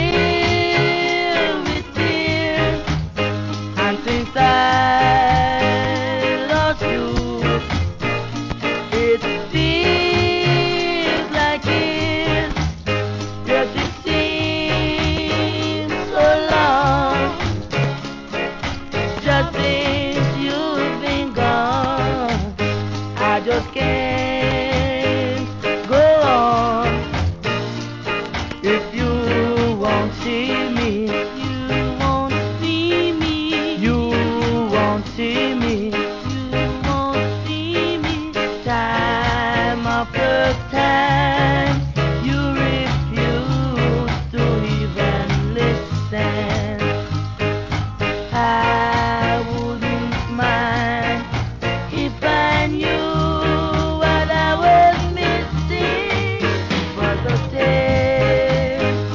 C (終盤で周期的なノイズ、SAMPLE確認ください。最後の最後で針飛びします。)
1. REGGAE